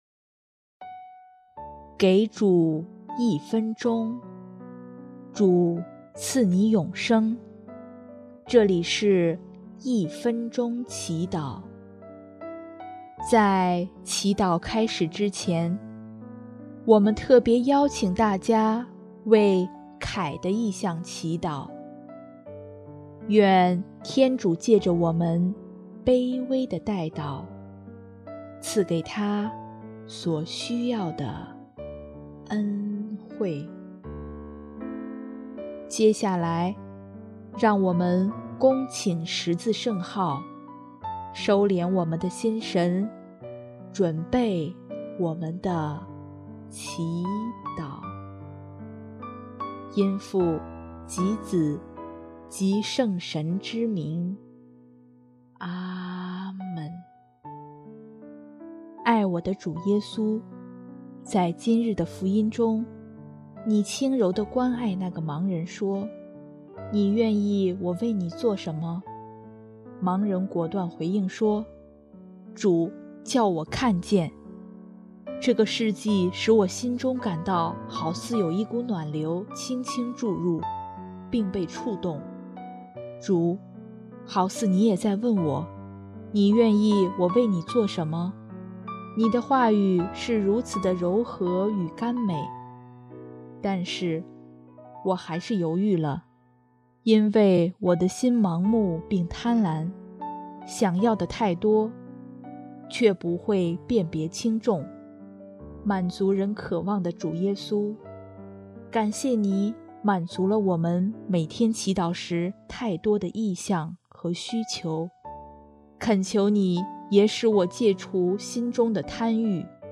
音乐：主日赞歌《看见》